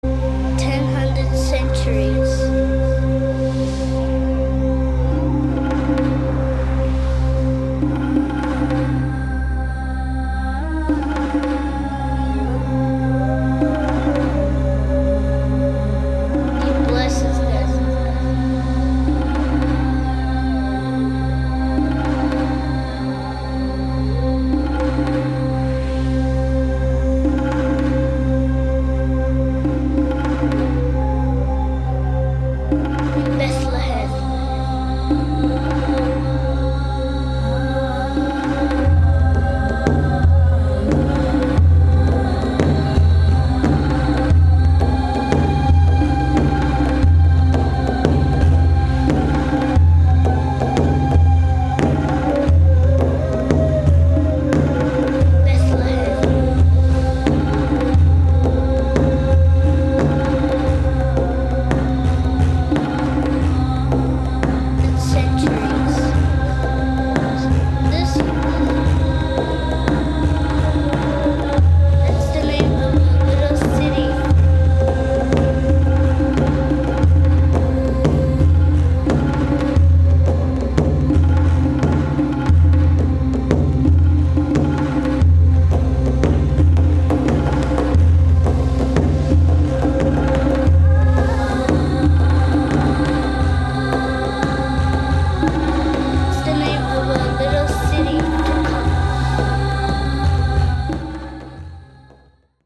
Genre: World Fusion.